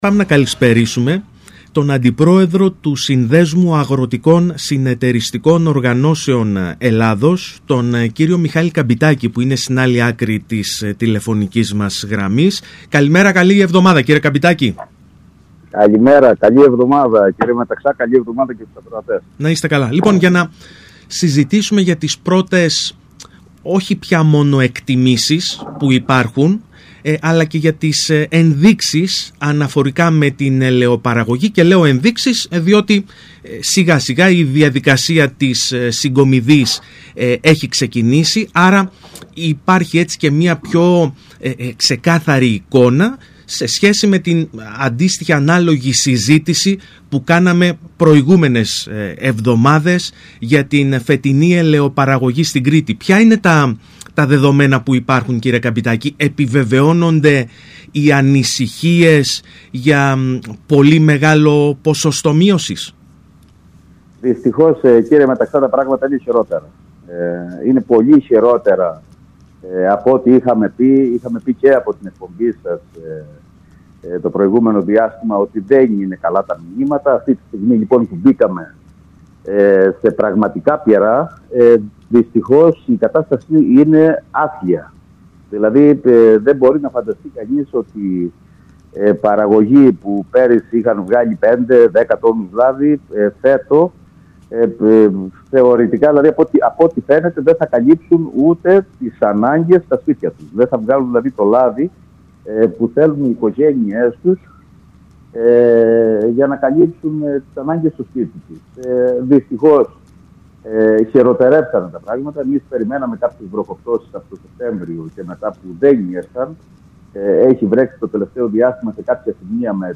Όπως είπε στον ΣΚΑΪ Κρήτης 92.1